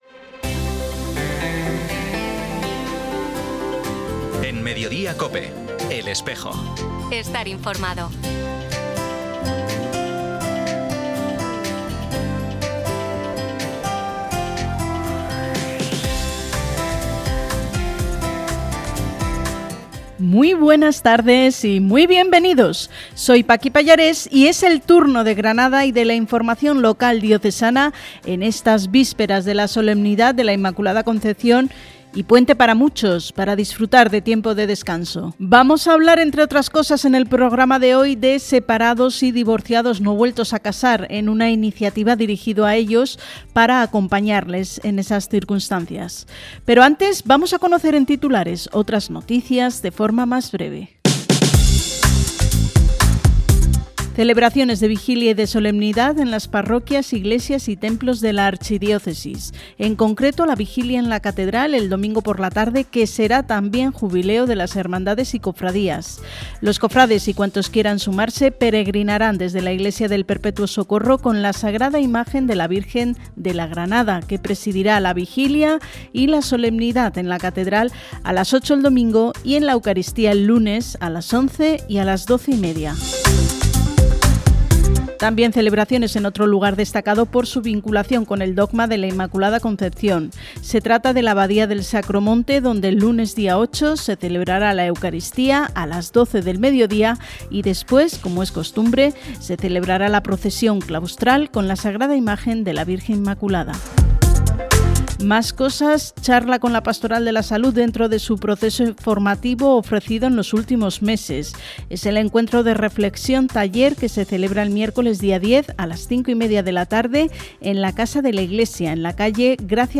Emitido en COPE Granada el 5 de diciembre de 2025.